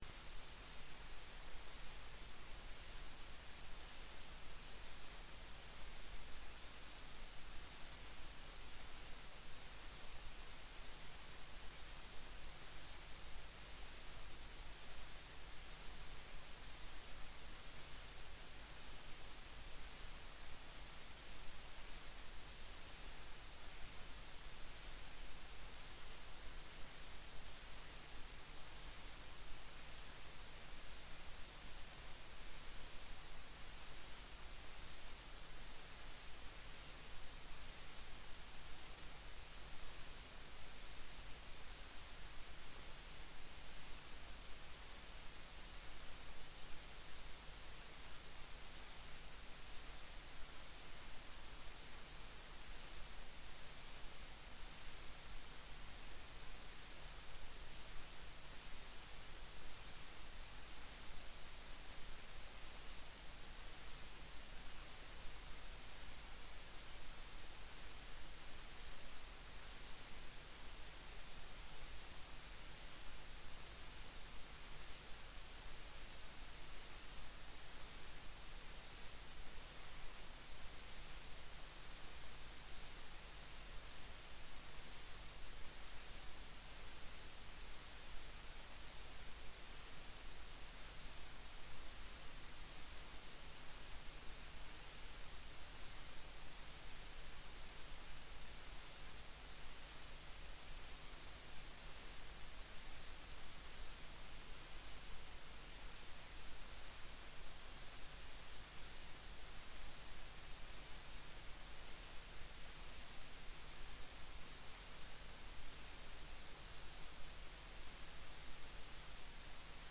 Rom: Store Eureka, 2/3 Eureka